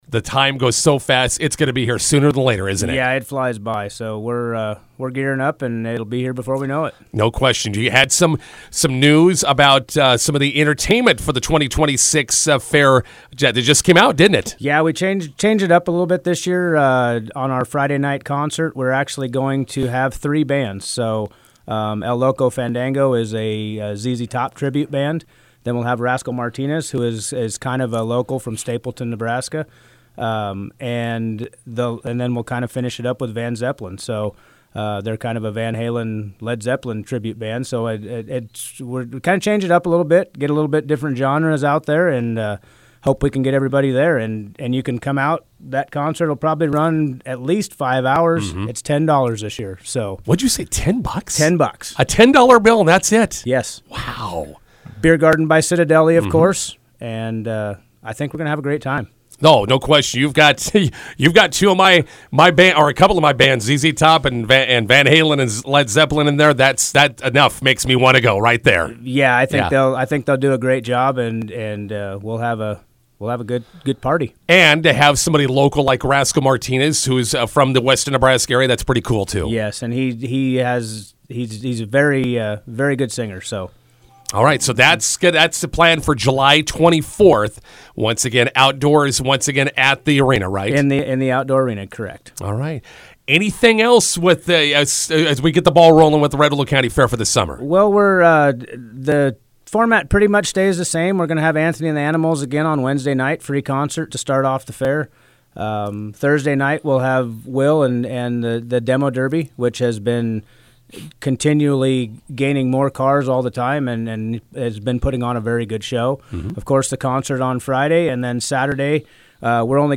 INTERVIEW: Red Willow County Fair announces their 2026 Fair Concert lineup.